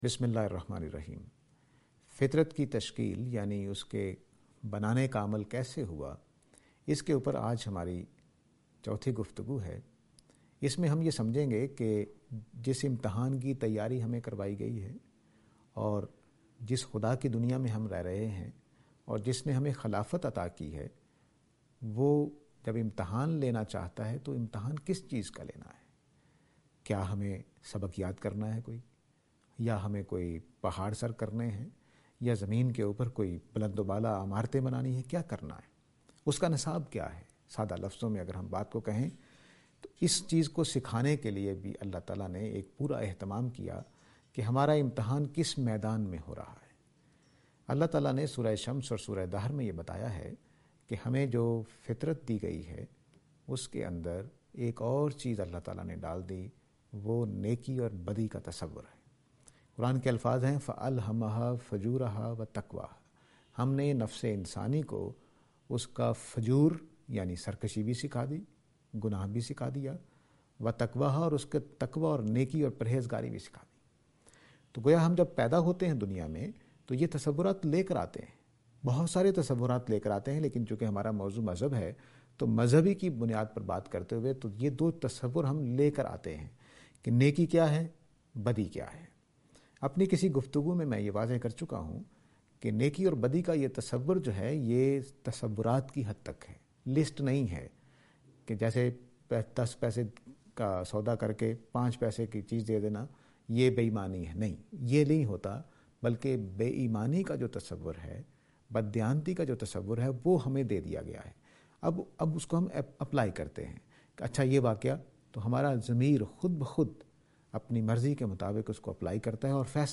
This lecture is and attempt to answer the question "Construction of Nature (Good and Evil)".